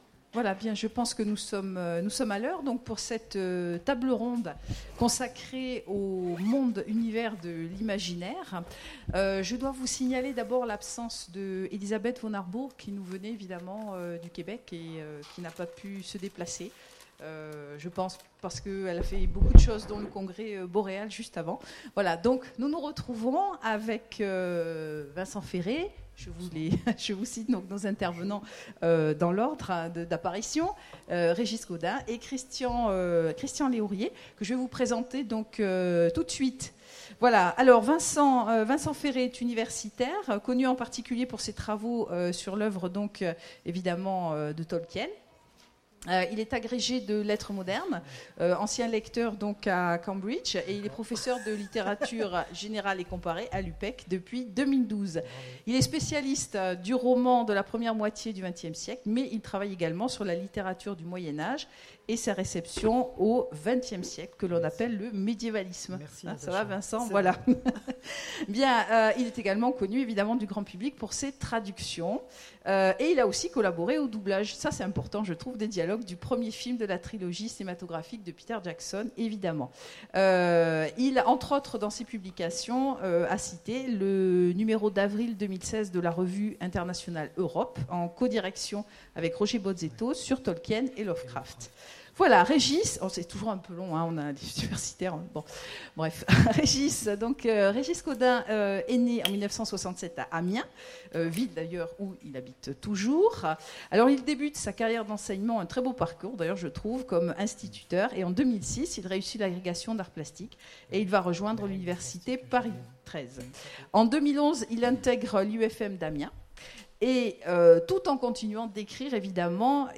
Imaginales 2016 : Conférence Les mondes-univers…